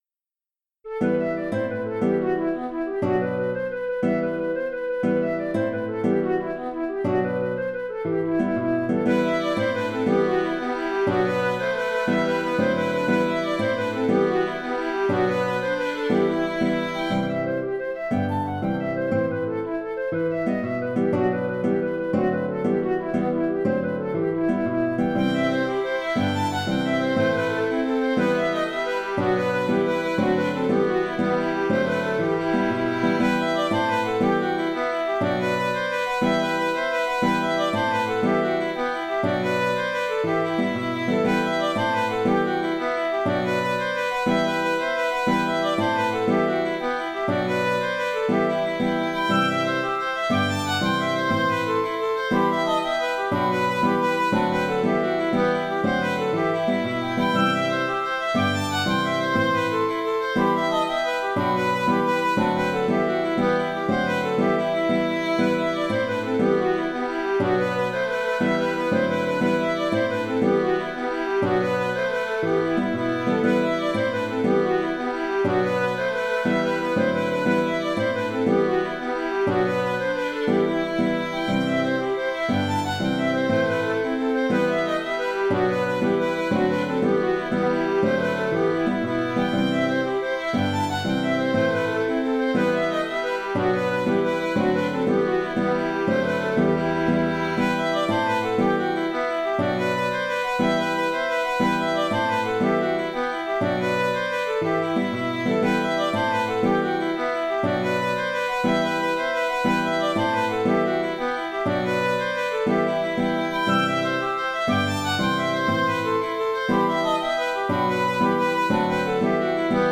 « Le jupon blanc » est une « jig » traditionnelle. Je propose deux contrechants qui doivent être interprétés comme un accompagnement de fond.
Il est évident que l’enregistrement numérique de la partition ne peut restituer l’interprétation du musicien..